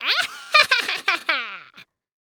horror
Scary Witch Cackle